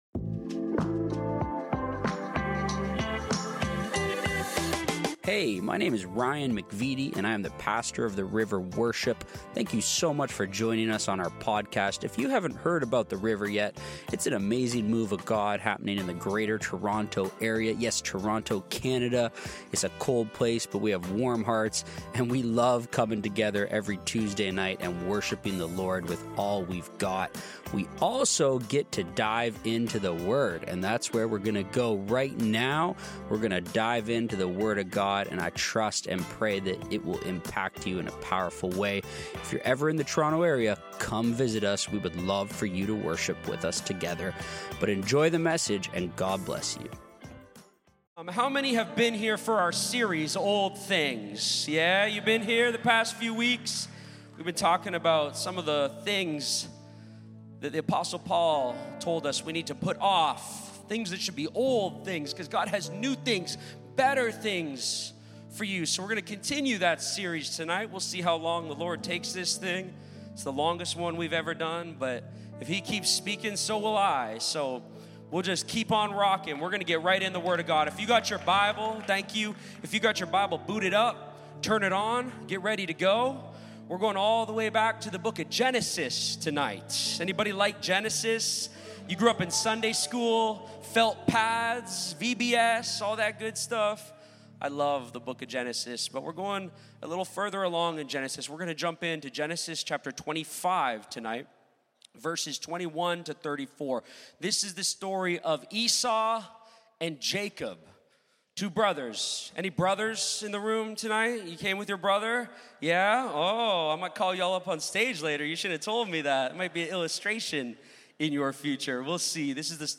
The River Worship - Sermons – Podcast